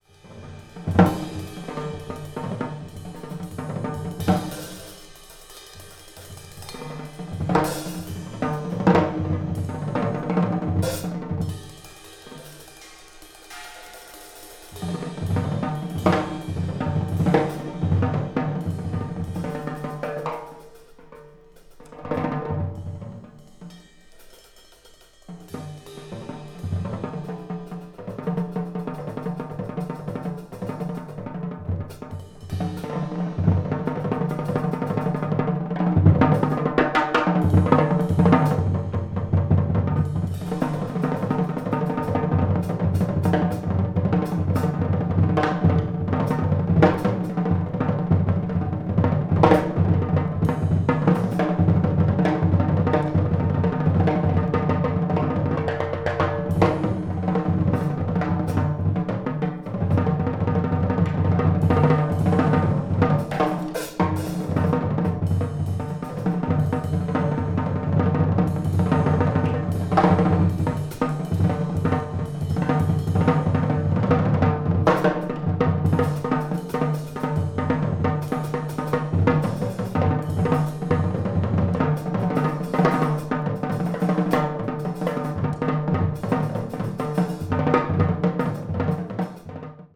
B面はグングンとテンションが上がり、怒涛のフリーへと突入。凄まじいライブです。
avant-jazz   free improvisation   free jazz   spiritual jazz